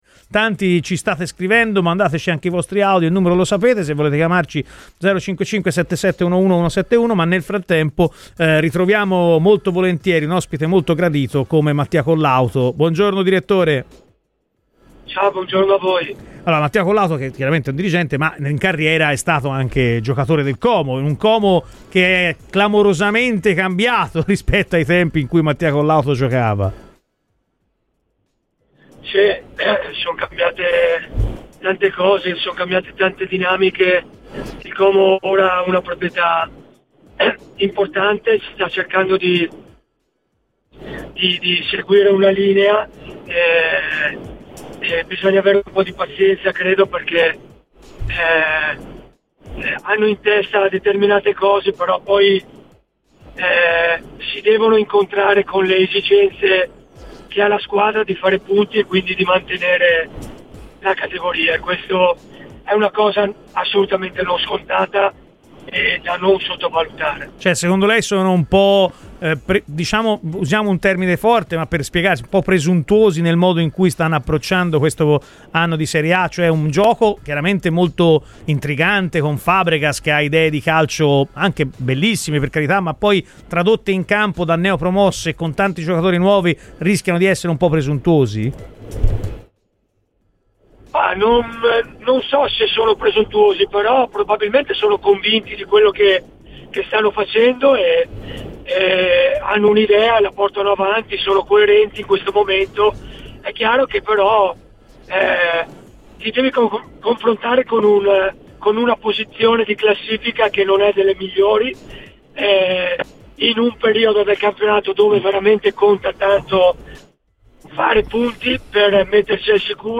è intervenuto l'ex calciatore del Como ora direttore sportivo